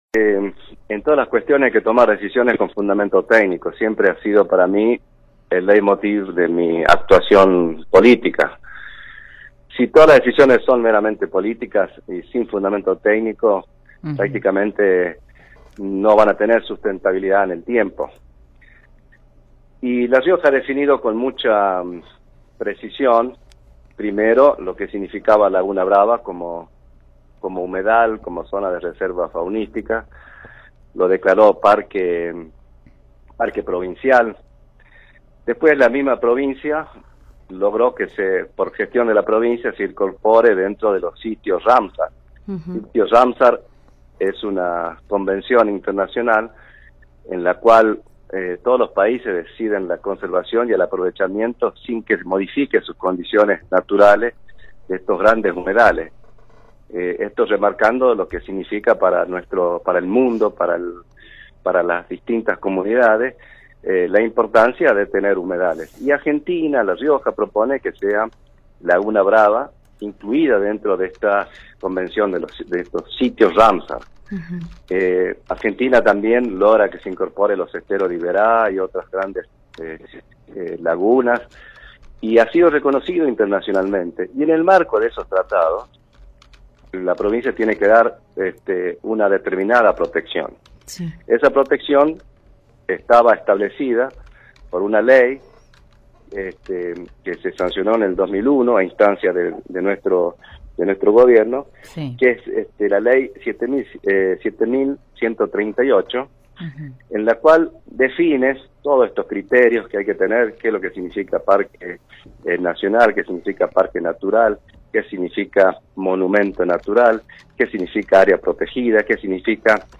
El ex gobernador Ángel Maza advirtió que el gobierno autorizó la exploración minera en Laguna Brava, ubicada en la cordillera riojana, próxima a la localidad de Vinchina. Pese que se trata de un humedal, Maza -ex secretario de Minería en la presidencia de Carlos Menem- dijo a radio Universidad que se modificó una ley para que ello ocurra.